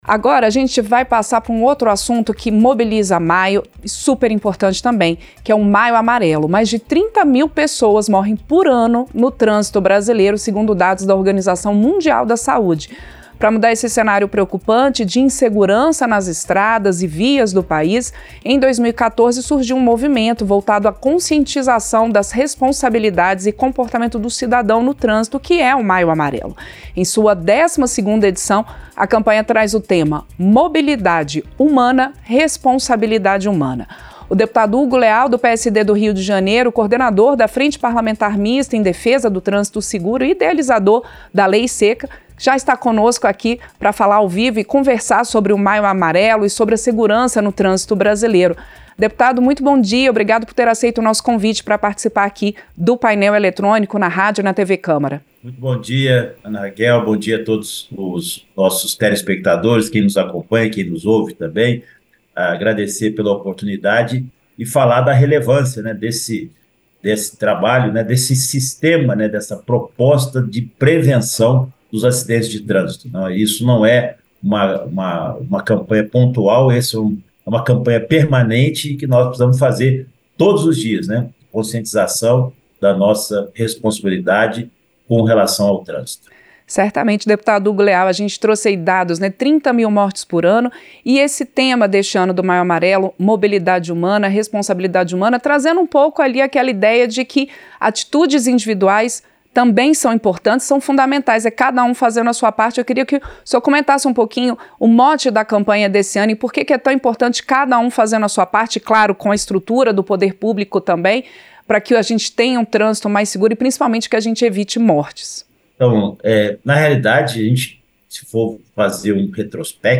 Entrevista - Dep. Hugo Leal (PSD-RJ)